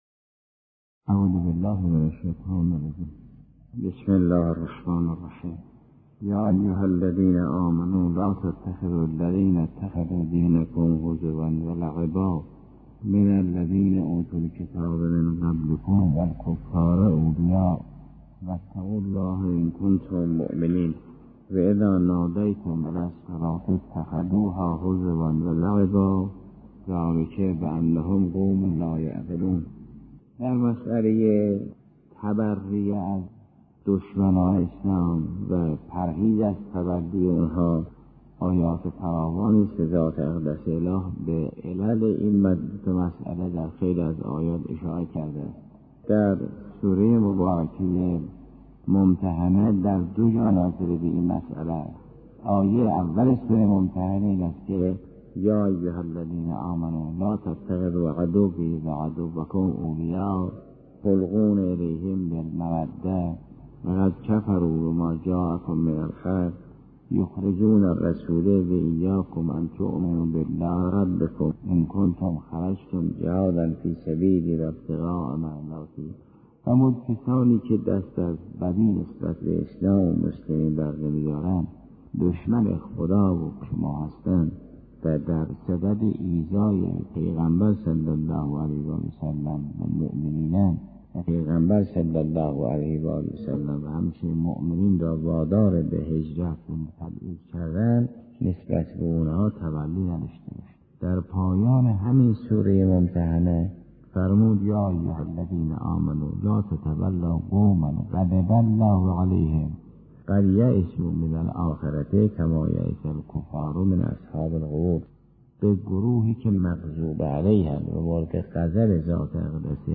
تفسیر سوره مائده جلسه 91